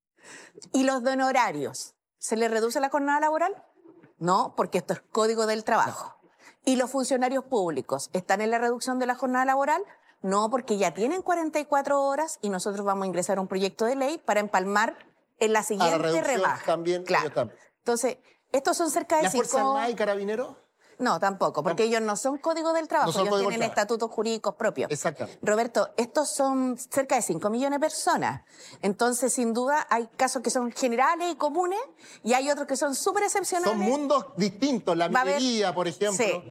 Jara respondió a las dudas más importantes sobre el dictamen en el matinal “Mucho Gusto”.